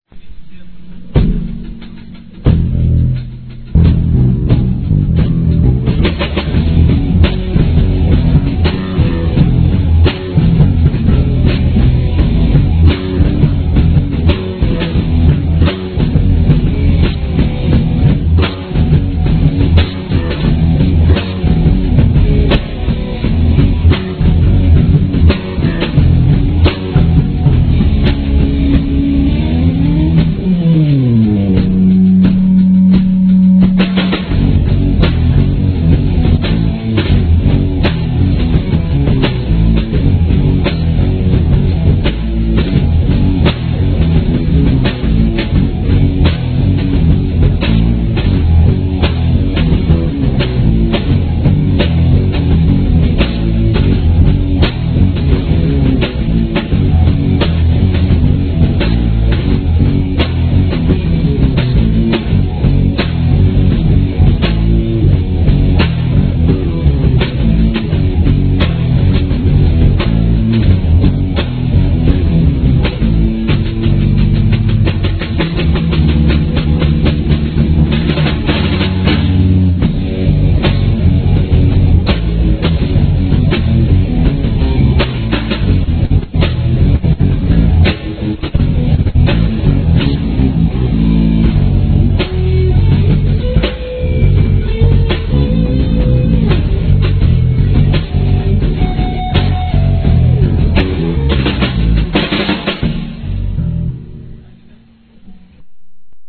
(instrumentale)